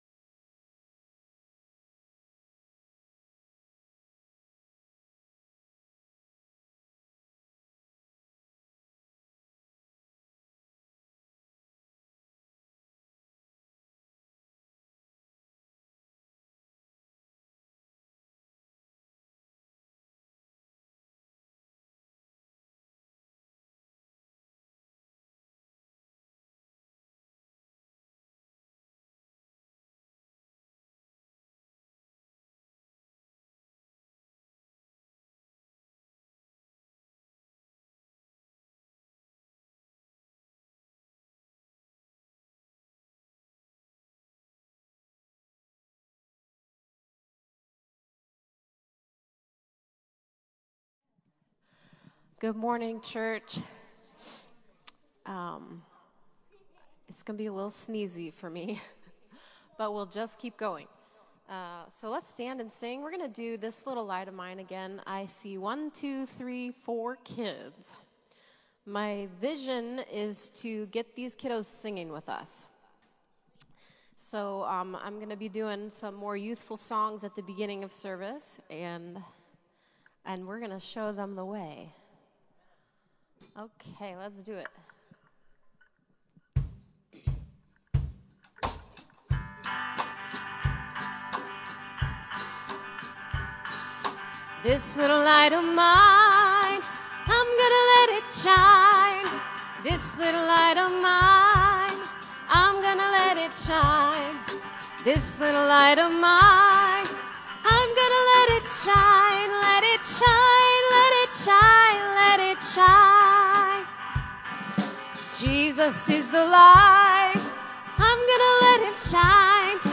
August 14 2022 Worship
Prayer Requests and Praises
Closing Prayer